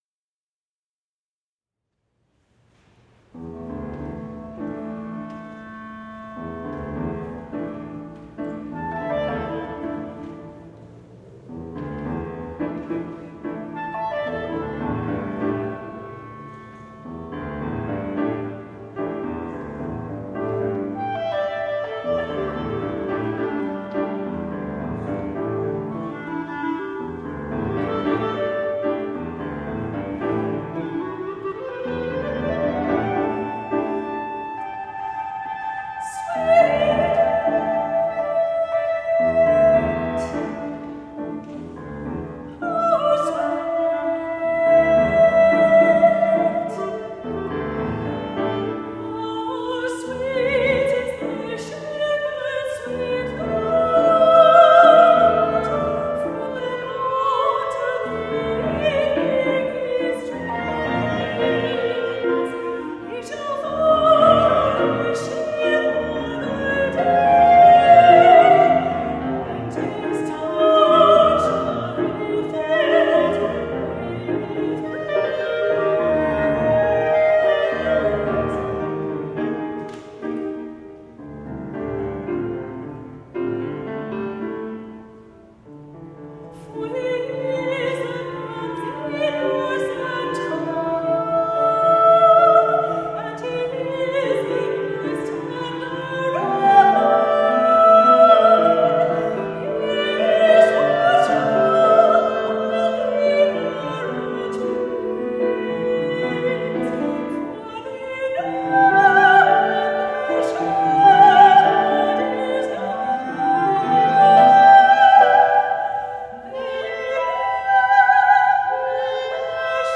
Soprano Voice